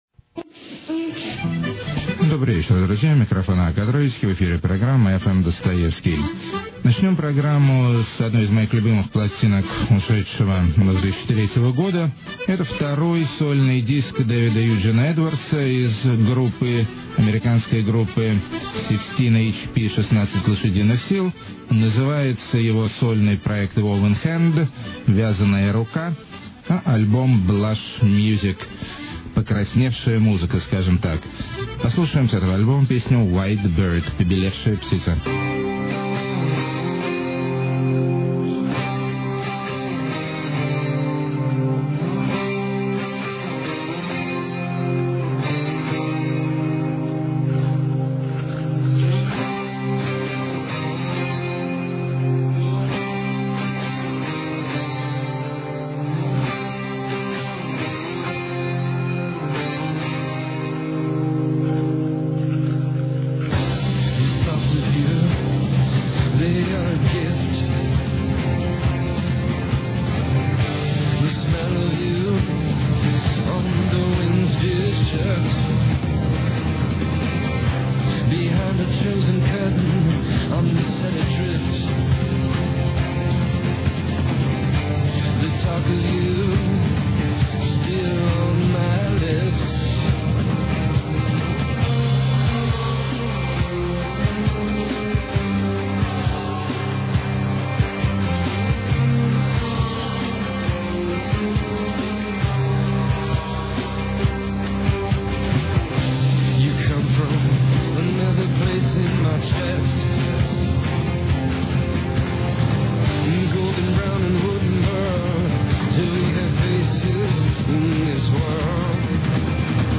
infantile beachy electronica
sexy neo bossa
noise-dub
part indie pop, part doom metal
beautiful dark minimalism
great garage screaming!
cabaret-style war songs
topical deep downtempo